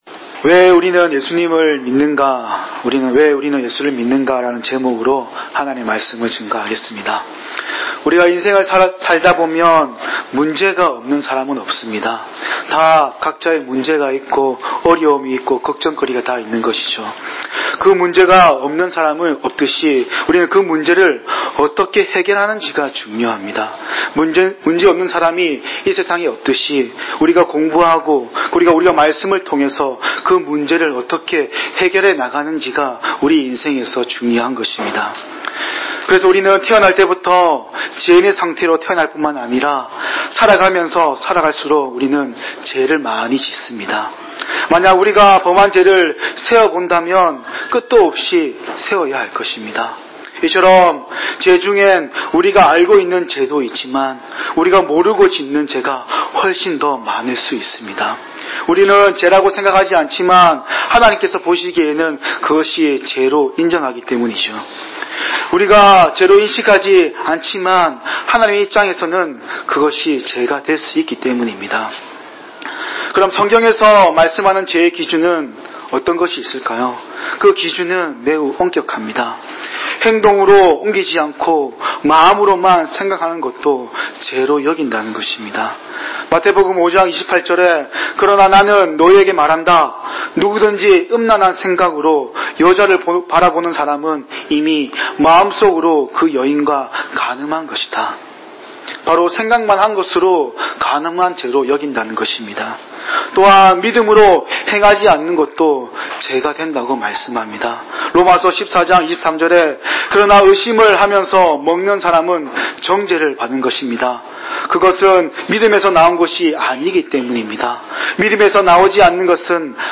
주일 목사님 설교를 올립니다. 2019년 1월 20일 “왜?